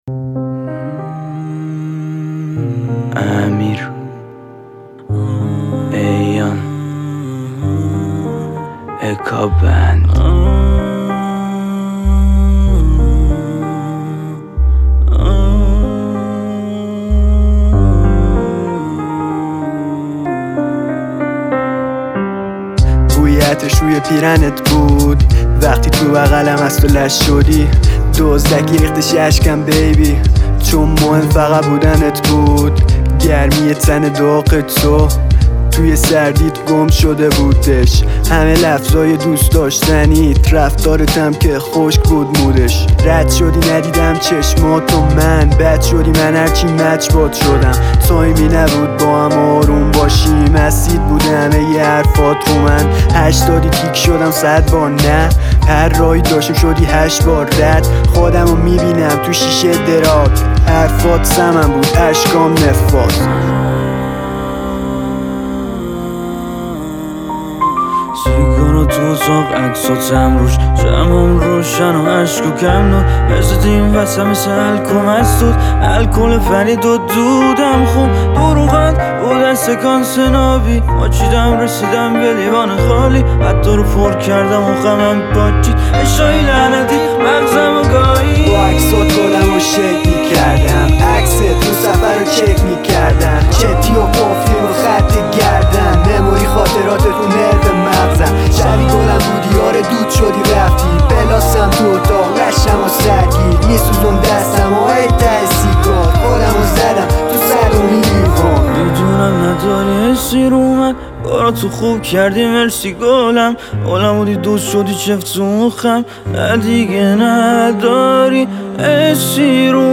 آهنگ جدید ایلام رپ